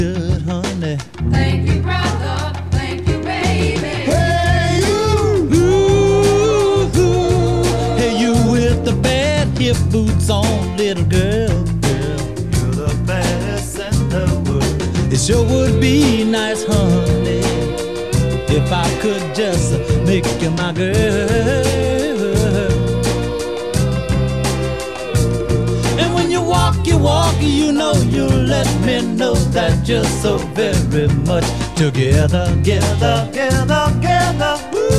Жанр: R&b / Рок / Соул